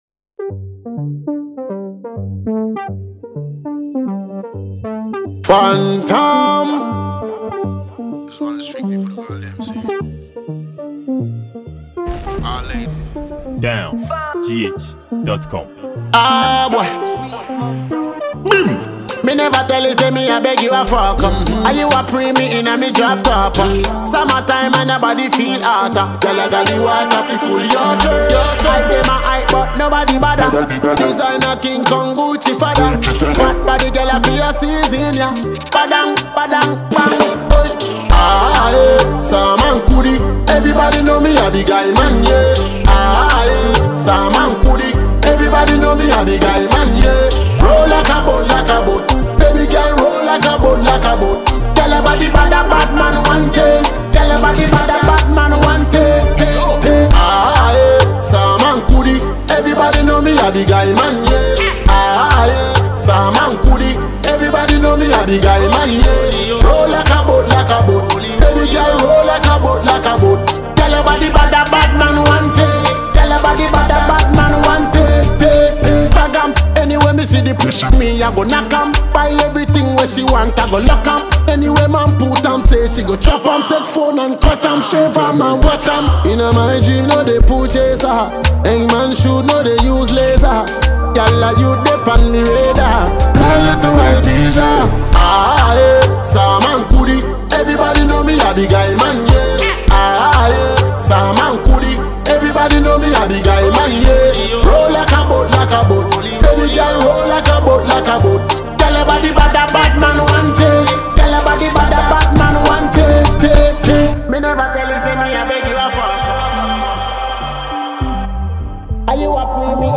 Genre: Afro dancehall